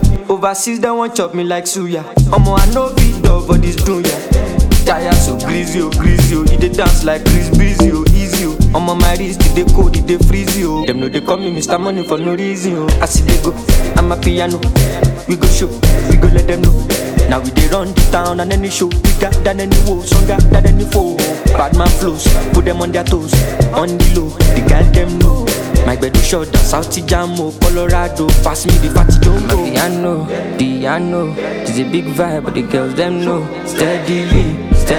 Жанр: Африканская музыка